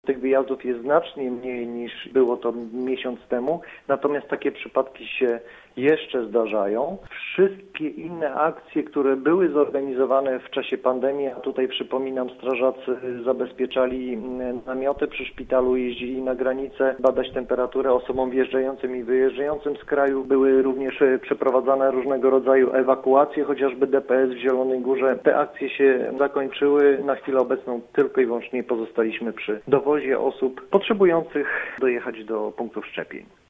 O tych działaniach mówił dziś na naszej antenie